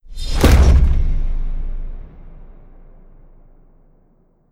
Broadcast_Player_Hit.wav